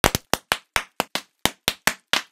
Paradise/sound/items/bubblewrap.ogg
bubblewrap.ogg